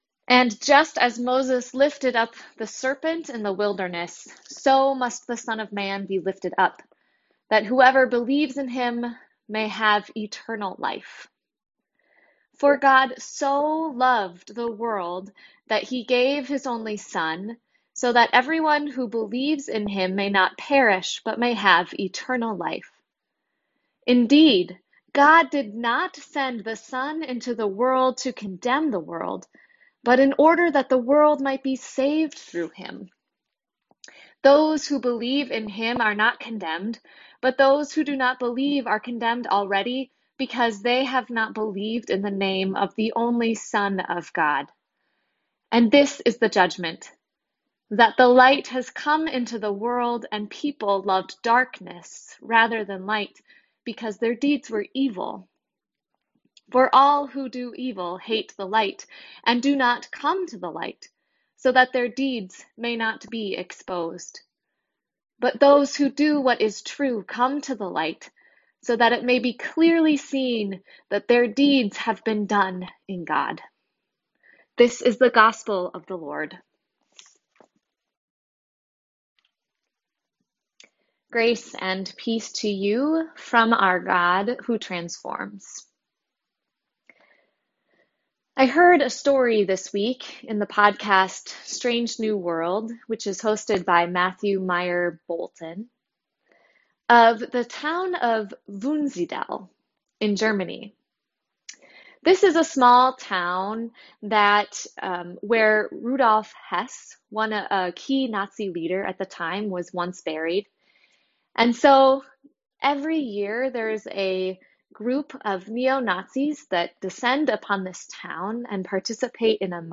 Welcome to worship with Christ the King! Today we hear from one of the most beloved passages of scripture - John 3.